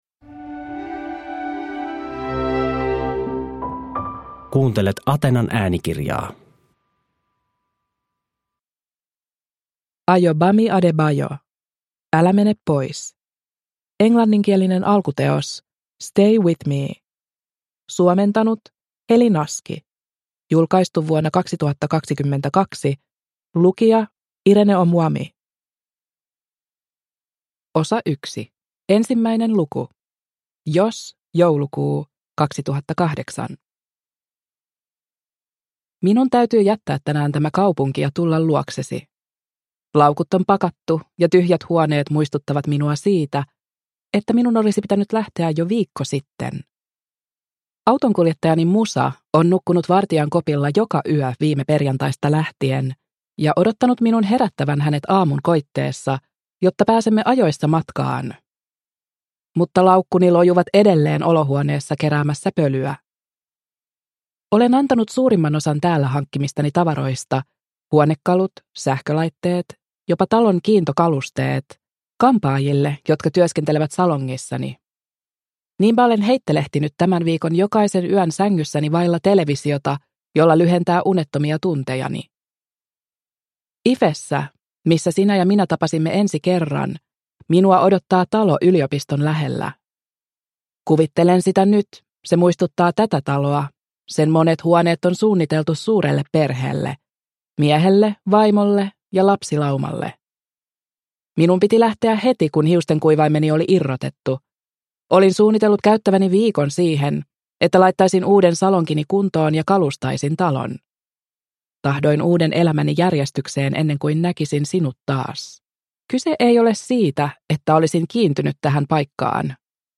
Älä mene pois – Ljudbok – Laddas ner